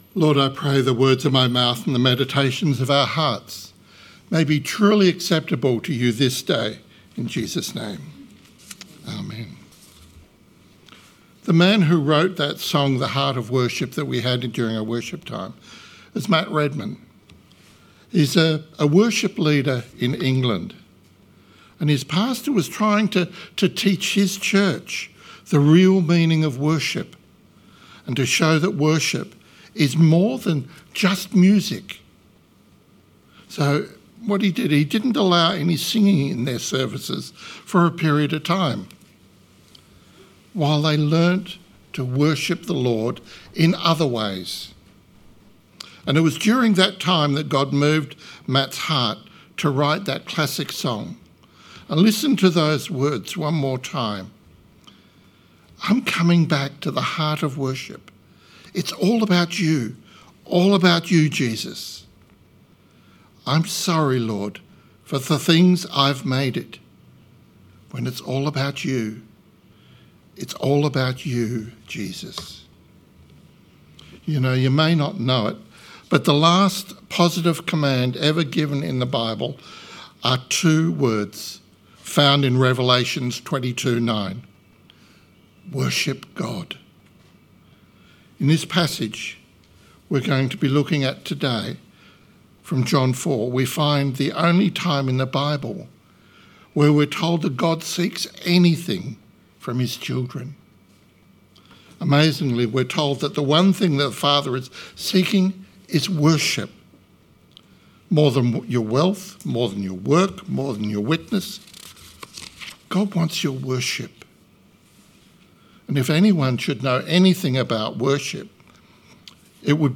Sermon 8th March 2026